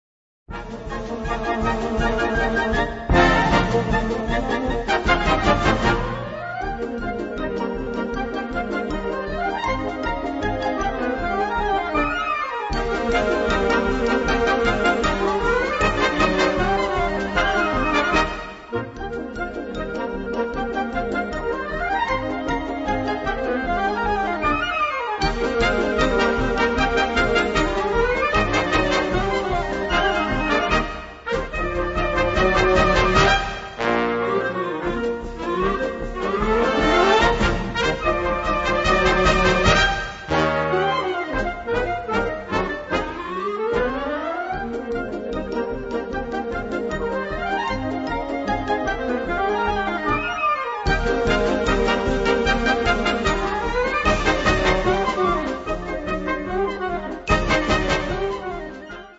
Gattung: Konzertstück
Besetzung: Blasorchester
Seine Besetzung fängt alle Orchesterfarben ein.